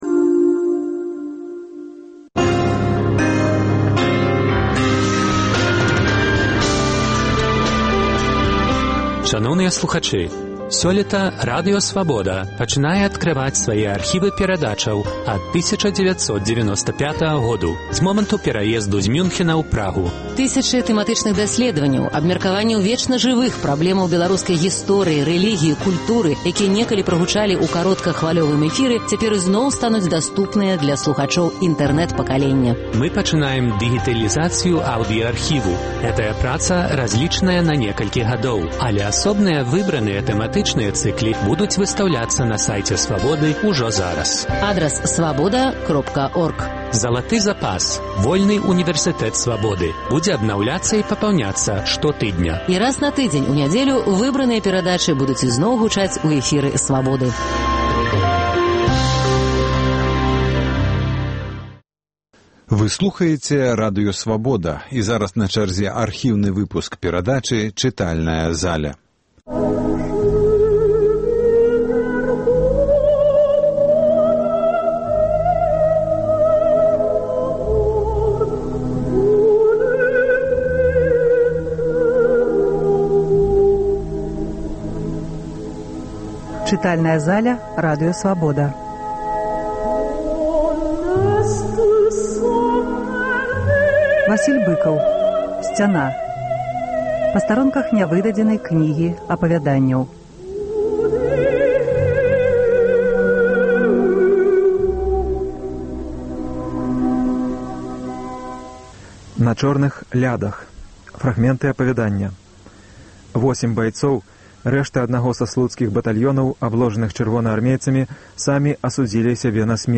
Васіль Быкаў чытае апавяданьні з кнігі "Сьцяна". Архіўны запіс 1997 году.